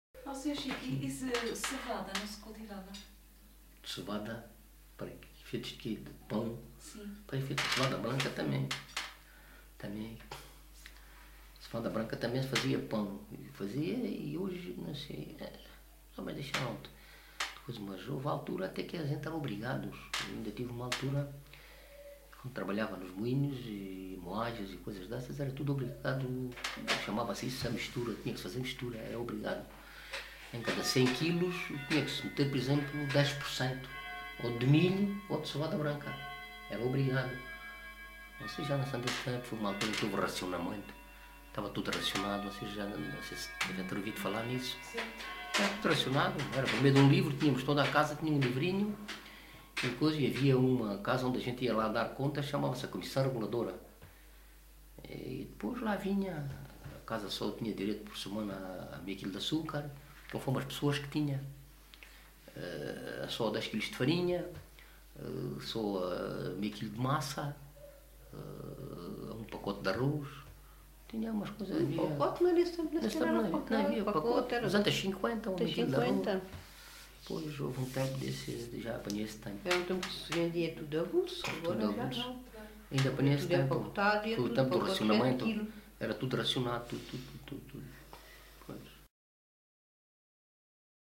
LocalidadeMontes Velhos (Aljustrel, Beja)